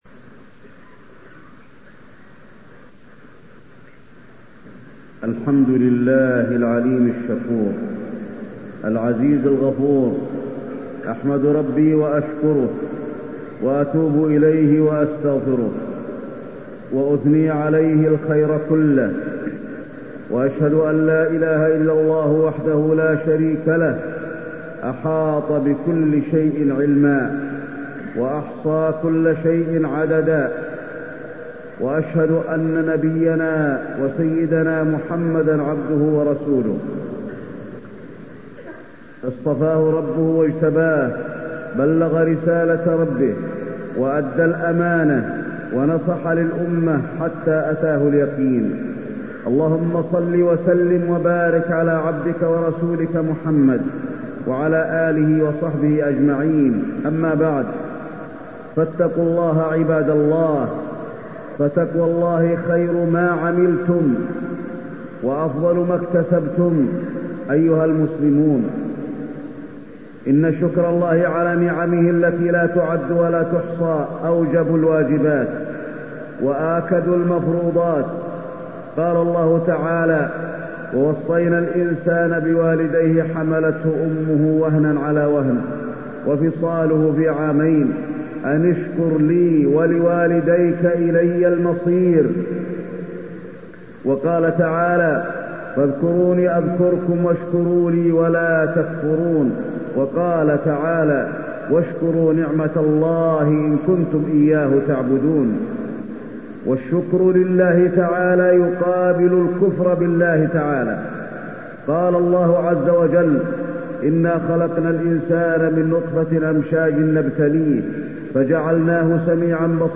تاريخ النشر ٤ شوال ١٤٢٤ هـ المكان: المسجد النبوي الشيخ: فضيلة الشيخ د. علي بن عبدالرحمن الحذيفي فضيلة الشيخ د. علي بن عبدالرحمن الحذيفي الشكر